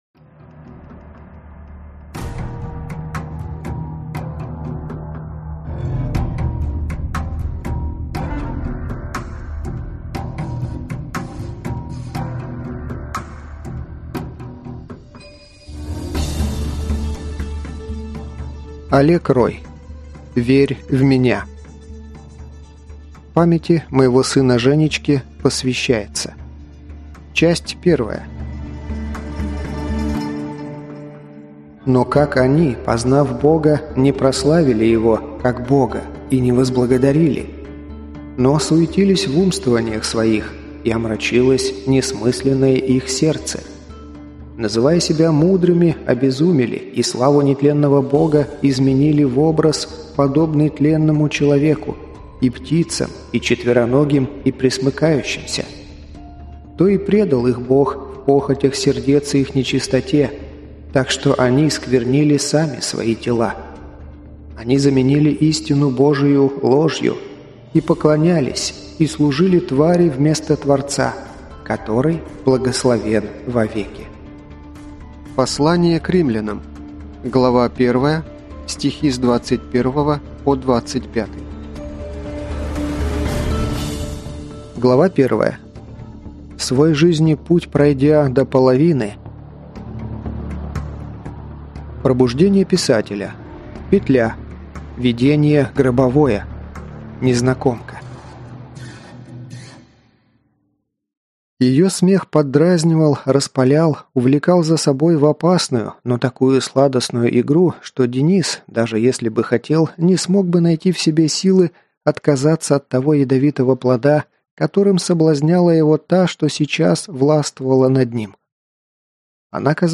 Аудиокнига Верь в меня - купить, скачать и слушать онлайн | КнигоПоиск